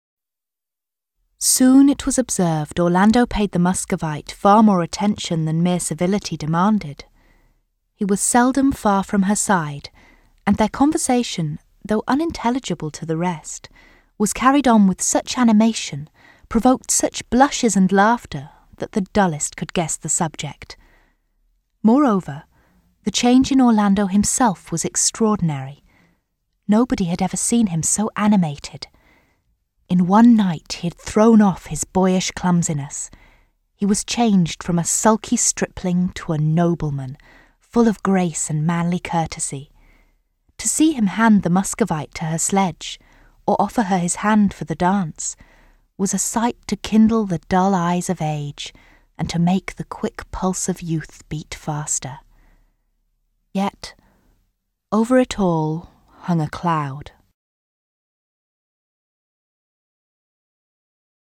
I have a bright, natural tone, and am an excellent sight-reader.
Reading 'Orlando' (Virginia Woolf)